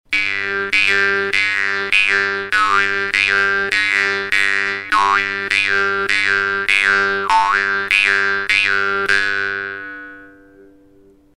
Die Wimmer-Bades Pro erinnert von der Form her an die sehr einfachen Modelle aus der gleichen Werkstatt, ihr Klang ist allerdings klar und die Stimmung sauber.
Ihre relativ straffe Zunge erzeugt einen lauten, scharfen Sound, der auch auf der Bühne mithalten kann.